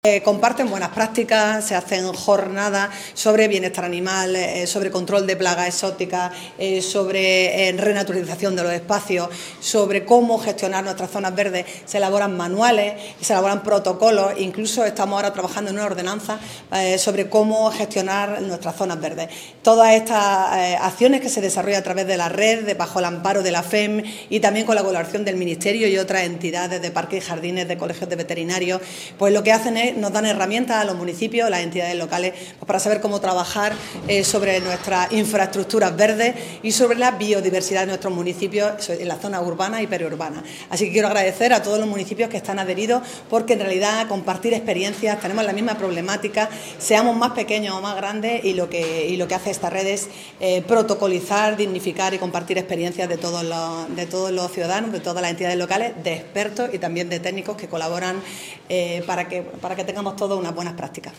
La alcaldesa de Almería y presidenta de la Red de Gobiernos Locales +Biodiversidad de la Federación Española de Municipios y Provincias (FEMP), María del Mar Vázquez, ha dado la bienvenida a la ciudad a los participantes en la XIII Asamblea de la Red de Gobiernos Locales +Biodiversidad que durante hoy y mañana van a poner el foco en la promoción de políticas locales para la conservación y uso sostenible de la biodiversidad y la conservación del patrimonio natural.
Es un gasto de presente, pero una inversión de futuro”, ha trasladado Vázquez durante su intervención desde el Salón Noble de la Casa Consistorial.